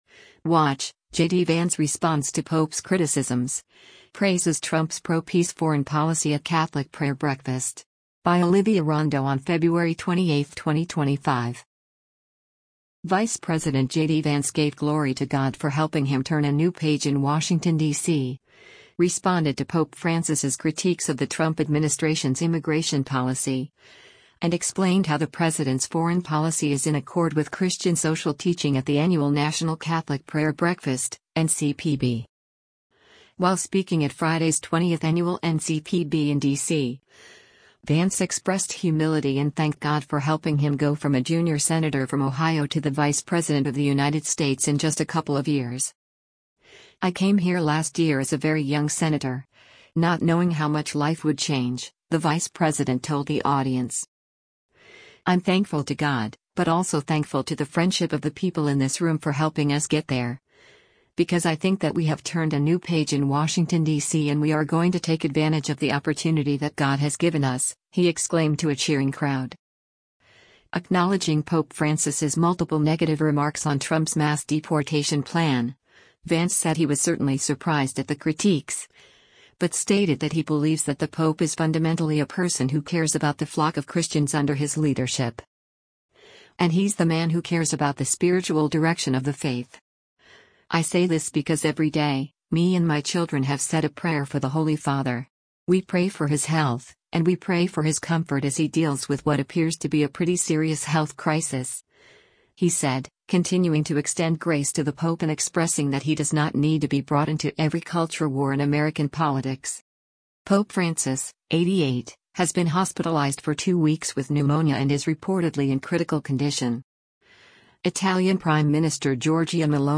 U.S. Vice President J.D. Vance speaks during the 20th annual National Catholic Prayer Brea
“I’m thankful to God, but also thankful to the friendship of the people in this room for helping us get there, because I think that we have turned a new page in Washington, DC and we are going to take advantage of the opportunity that God has given us,” he exclaimed to a cheering crowd.